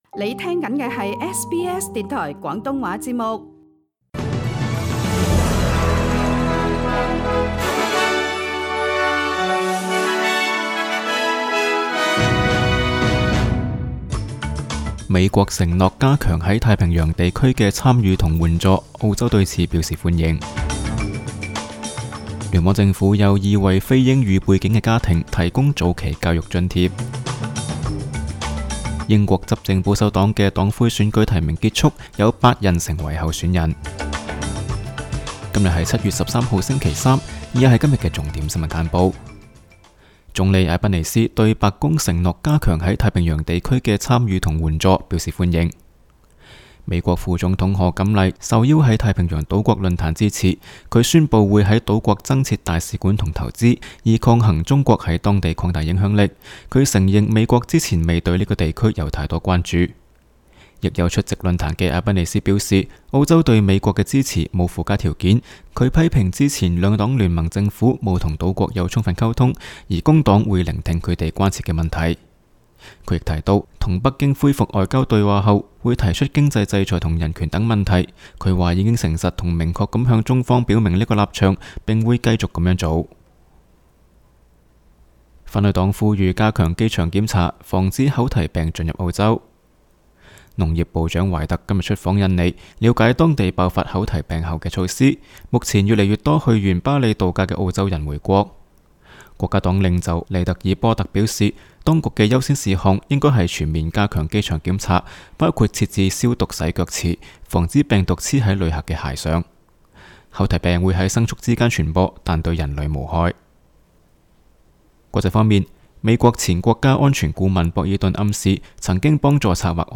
SBS 新闻简报（7月13日）
SBS 廣東話節目新聞簡報 Source: SBS Cantonese